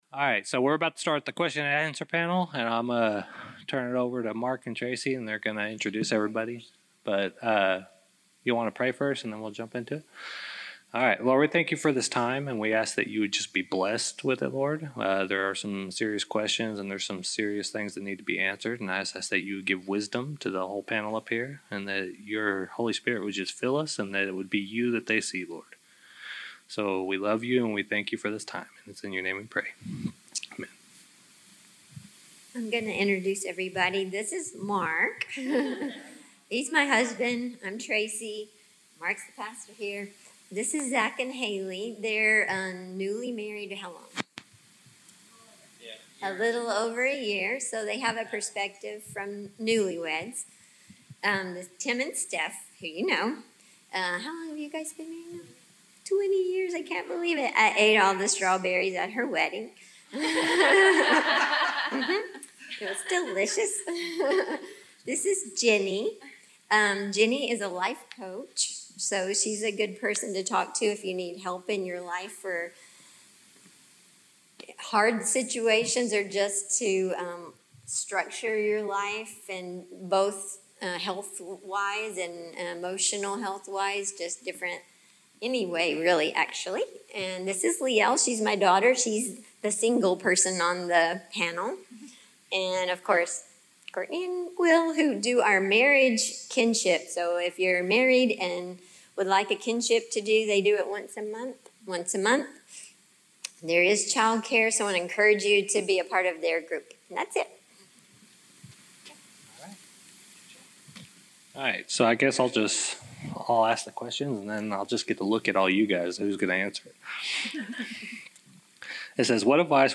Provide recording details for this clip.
2021 Relationship Conference